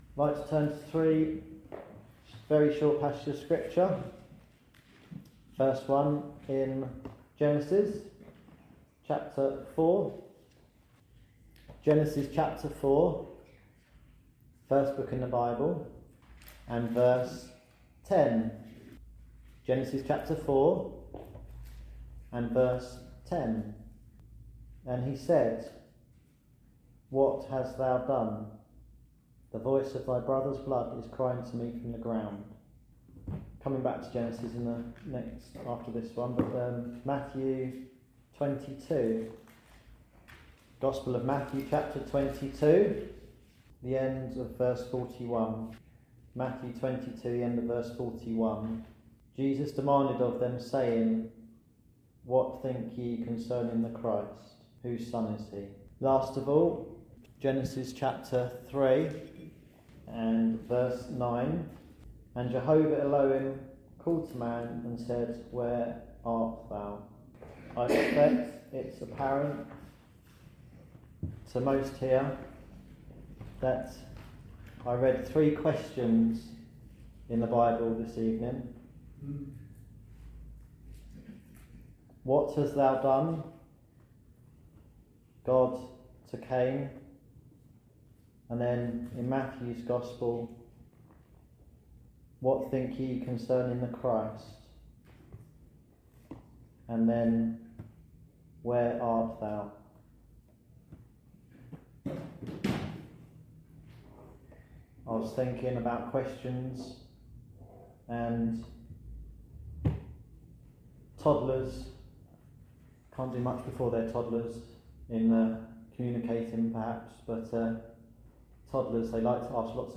Discover three vital questions from God’s Word: What hast thou done? What think ye concerning the Christ? Where art thou? A Gospel message calling response to these three important questions.